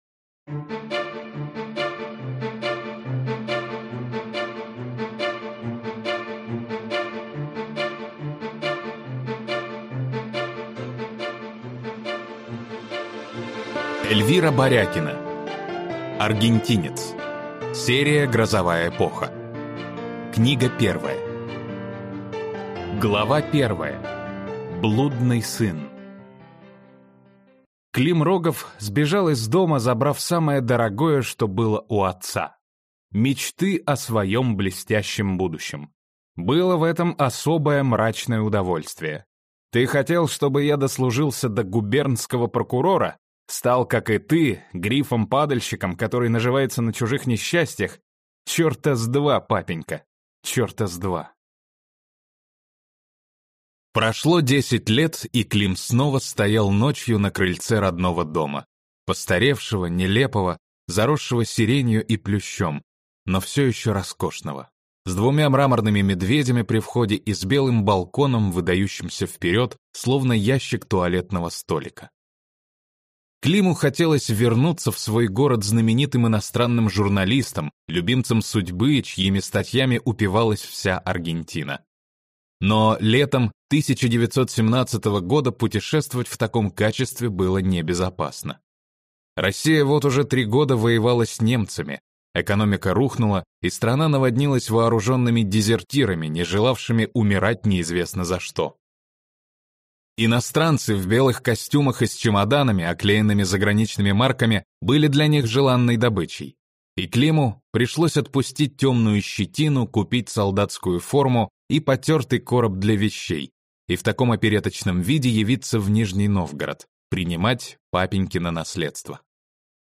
Аудиокнига Аргентинец | Библиотека аудиокниг